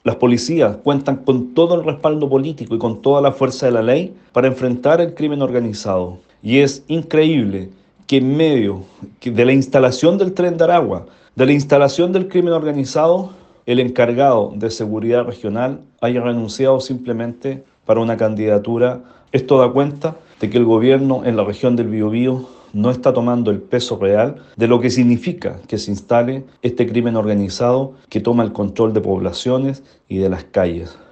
Más allá fue el diputado por la zona y jefe de bancada de la Democracia Cristiana, Eric Aedo, quien lamentó la ausencia de un jefe de Seguridad Pública en el Bío Bío, ante el aumento del crimen organizado.